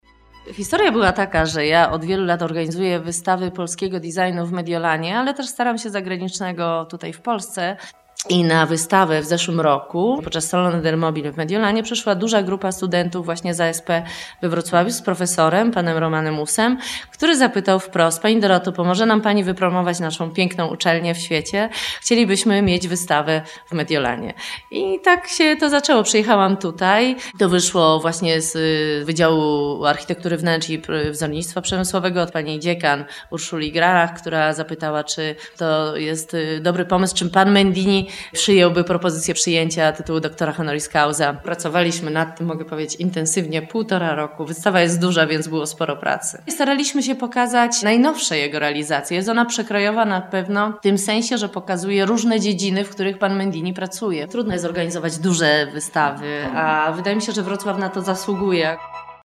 "Mendini. Maestro del Design" – relacja z otwarcia wystawy